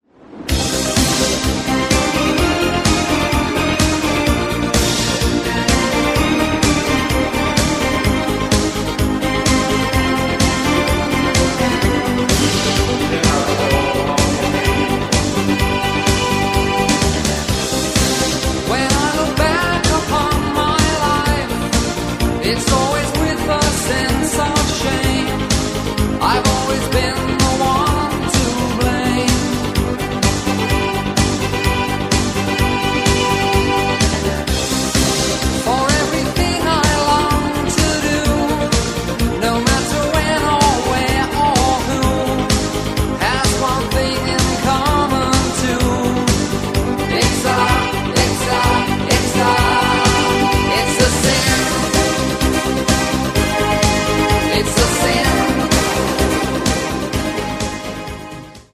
• Качество: 320, Stereo
поп
мужской вокал
Synth Pop
дуэт
танцевальные
Дискомузыка от британского синтипоп-дуэта